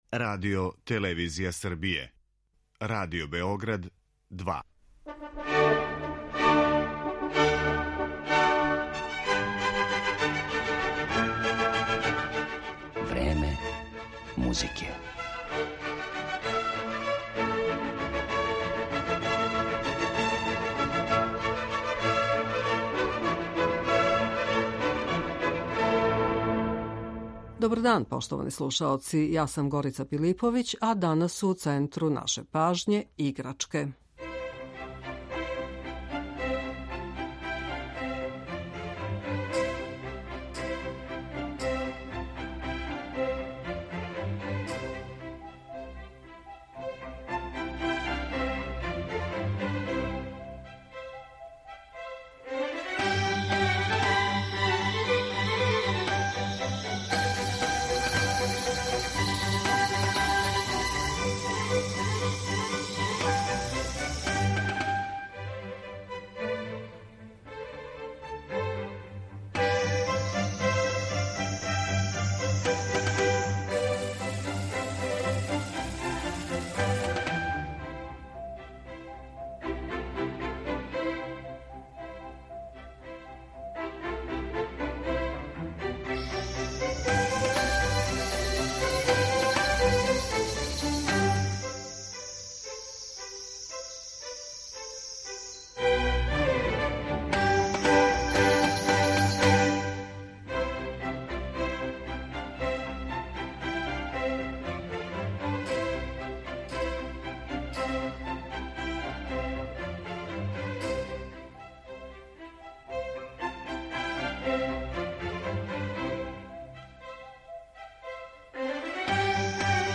И предвидео је читав низ инструмената-играчака - малу трубу, чегртаљку, зов кукавице, славуја и препелице, звончиће, тријангл, бубањ и глокеншпил.